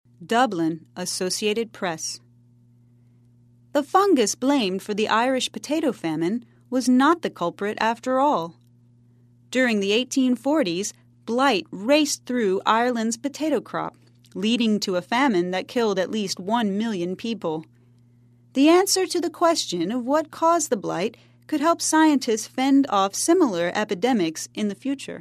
在线英语听力室赖世雄英语新闻听力通 第66期:谁是罪魁祸首的听力文件下载,本栏目网络全球各类趣味新闻，并为大家提供原声朗读与对应双语字幕，篇幅虽然精短，词汇量却足够丰富，是各层次英语学习者学习实用听力、口语的精品资源。